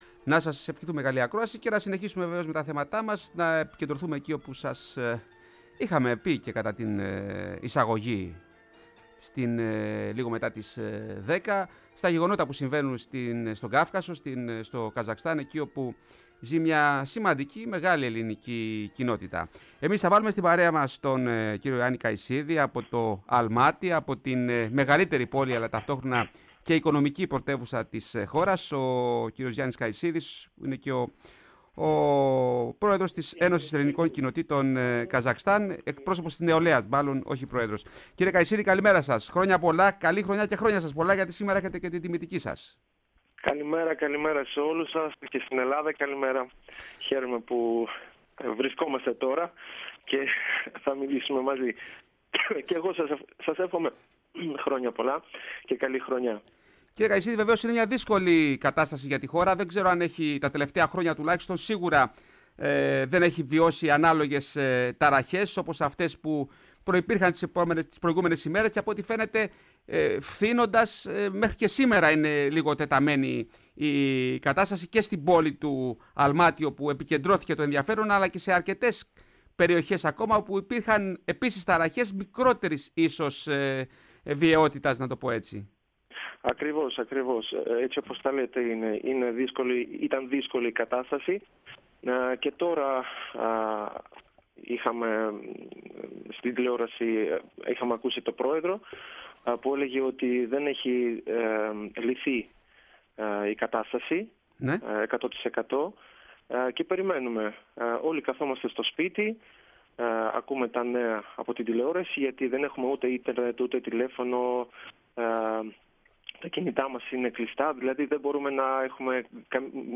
Μαρτυρία Έλληνα από το Καζακστάν στη “Φωνή της Ελλάδας”